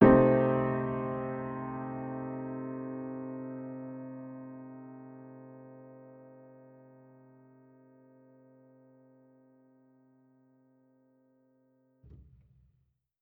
Index of /musicradar/jazz-keys-samples/Chord Hits/Acoustic Piano 2
JK_AcPiano2_Chord-Cm6.wav